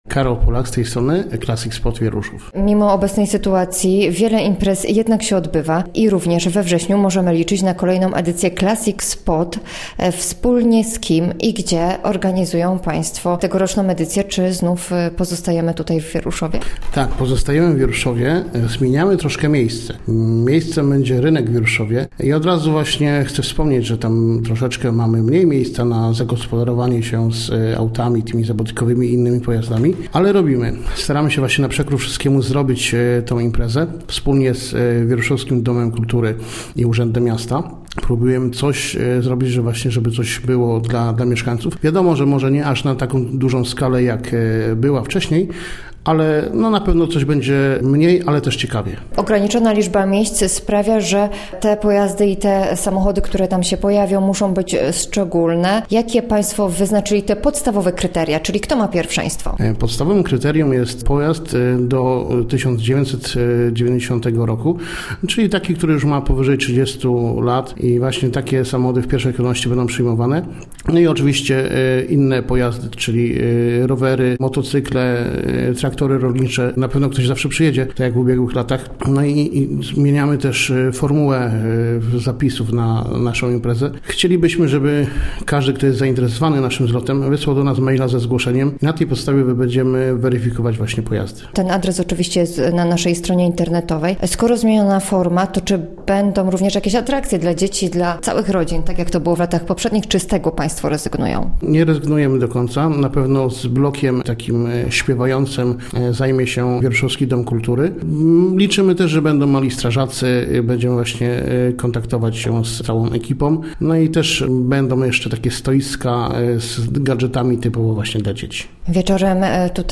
6 września w wieruszowskim Rynku wystartuje Classic Spot 2020 – Wehikuł Czasu. Ta wyjątkowa impreza zrzeszająca miłośników zabytkowych pojazdów z całej Polski, w tym roku odbędzie się z zmienionej formie. Szczegóły w rozmowie